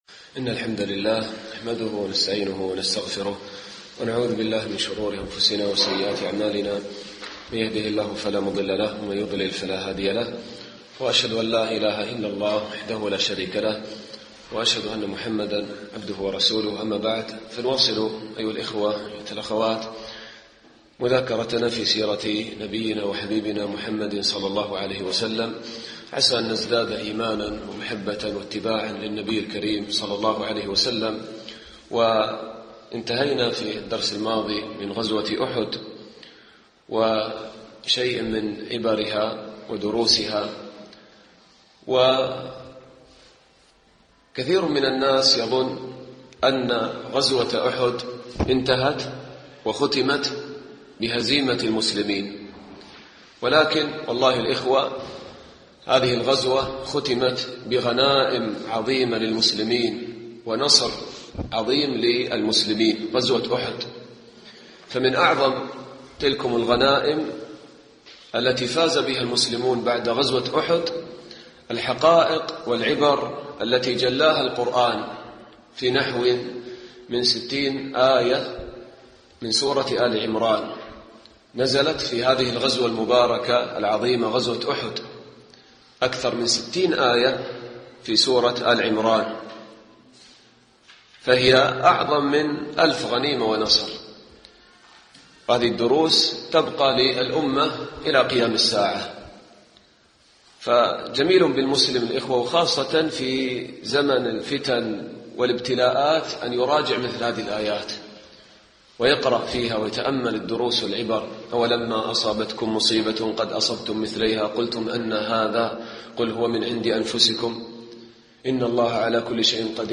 الدرس الثاني عشر